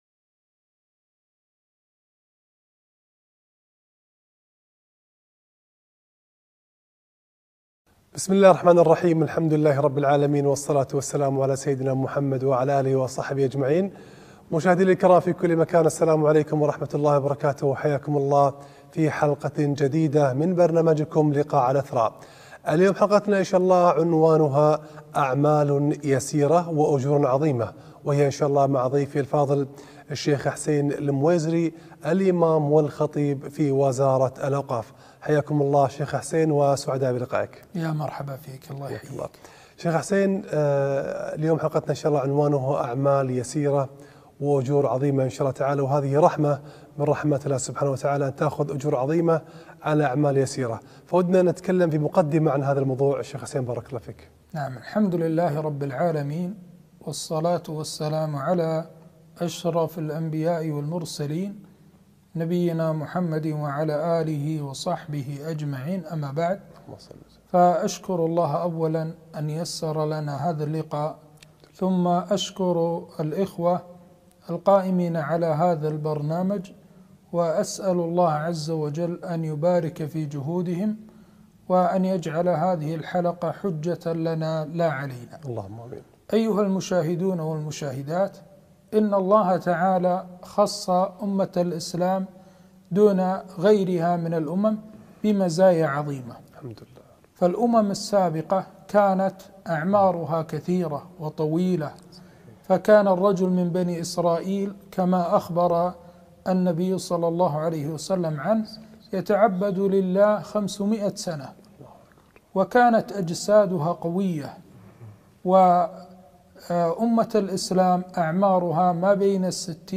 أعمال يسيرة وأجور عظيمة - لقاء